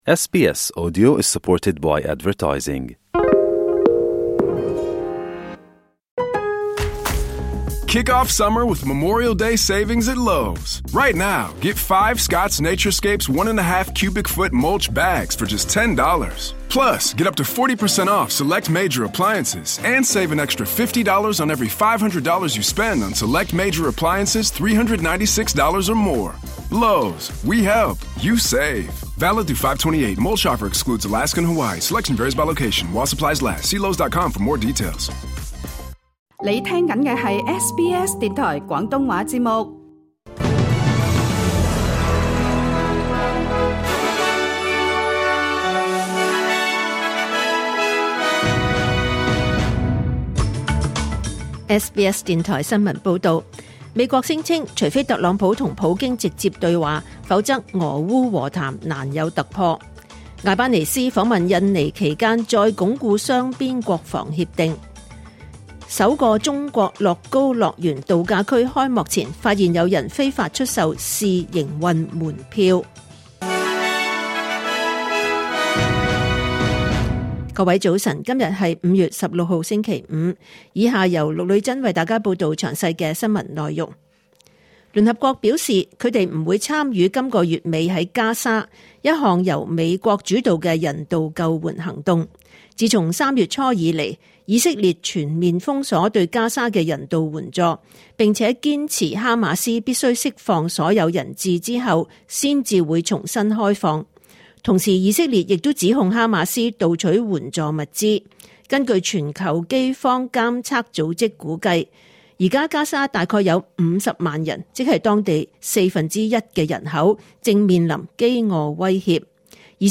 2025年5月16日 SBS 廣東話節目九點半新聞報道。